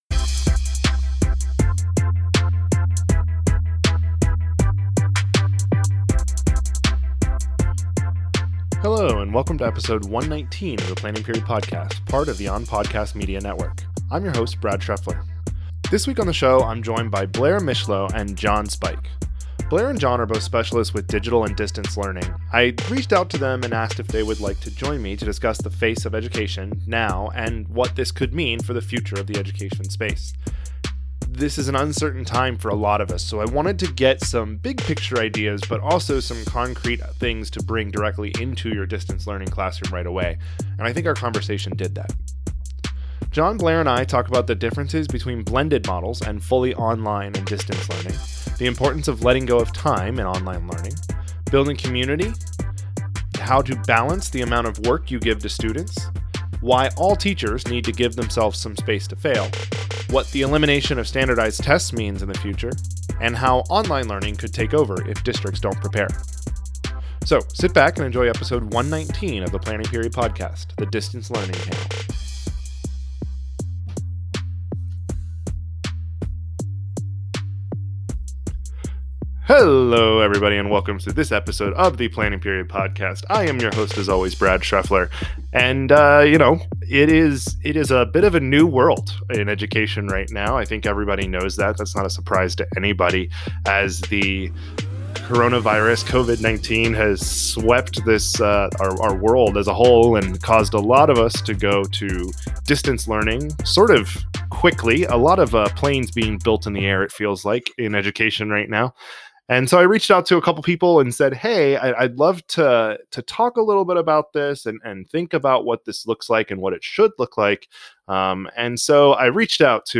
Distance Learning Panel